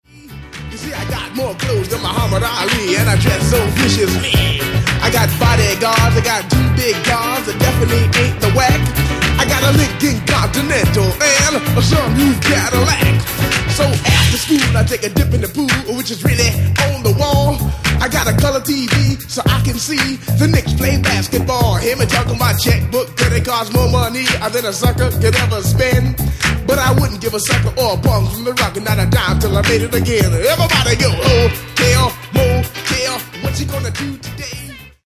Genere:    Disco | Funk